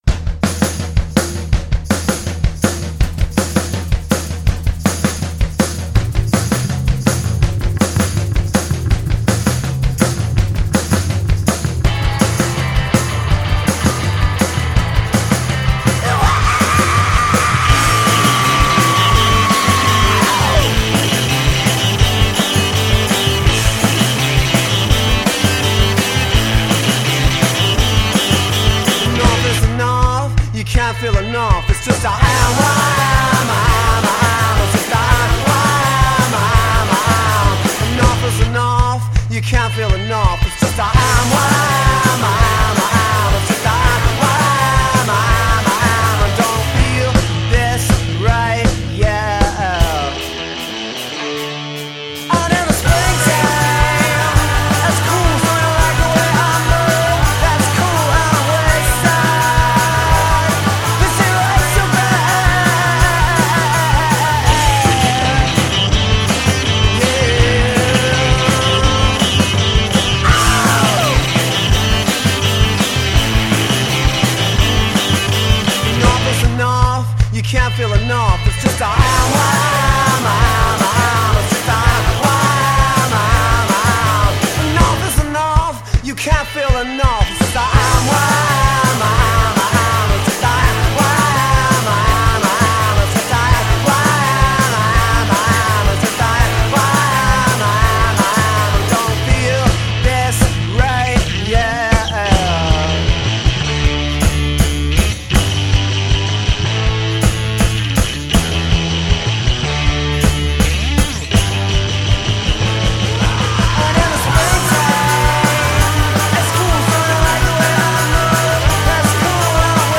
12 piece garage pop act